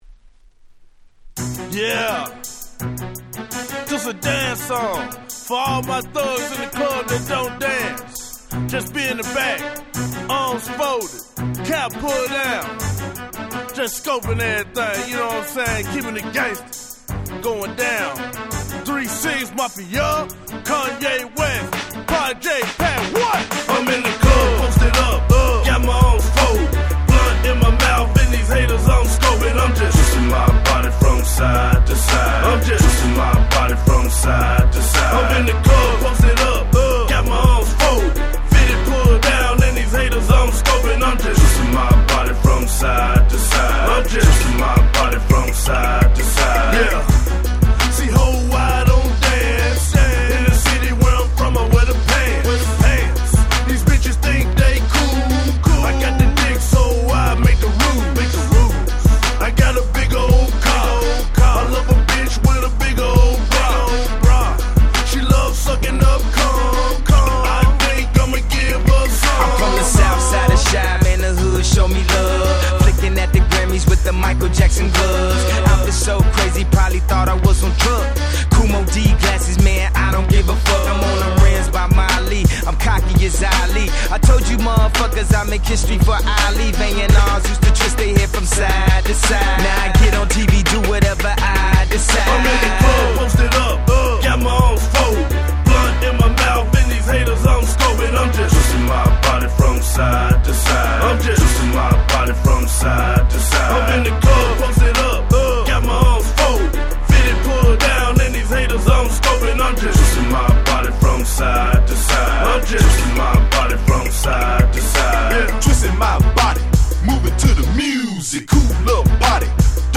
06' Smash Hit Southern Hip Hop !!
いつも通り彼らのカラー100%なサウスバウンスチューン！！